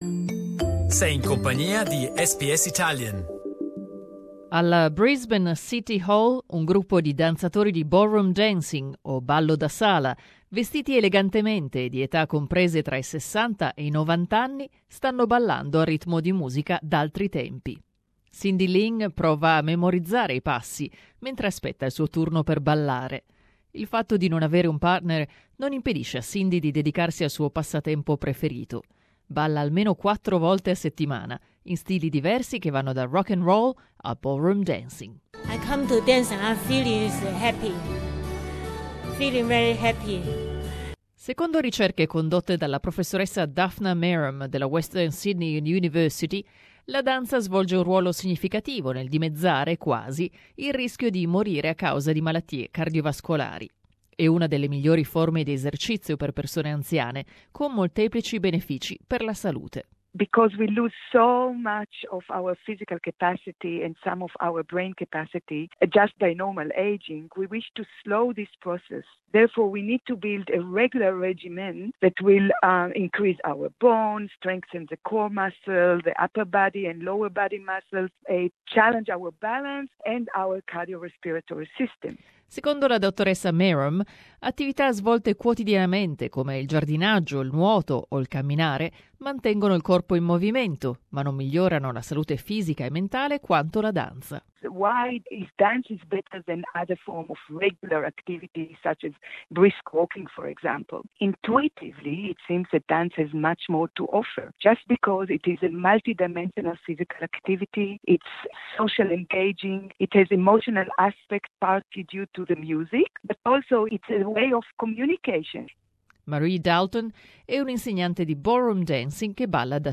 At the basement of Brisbane City Hall, a group of elegantly dressed ballroom dancers aged from theirs sixties to nineties are dancing away to old time music.